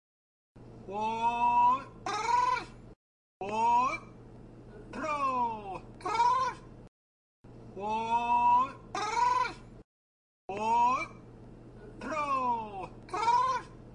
召唤骆驼
描述：沙特阿拉伯的一些地区的人们在呼唤动物，尤其是骆驼时会使用这种声音。
标签： 动物园 现场录音 动物
声道立体声